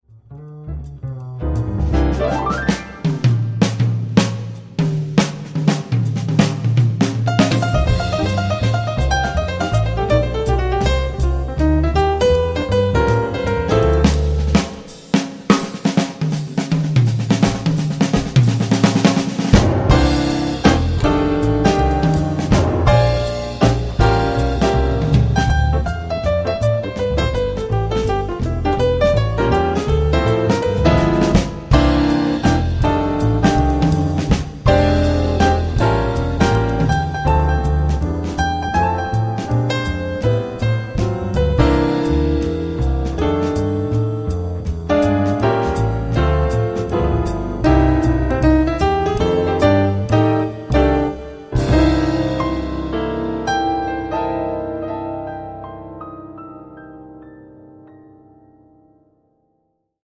將古典大師的作品改編成爵士三重奏的型式，讓高雅的 古典曲目增添了輕快寫意的風味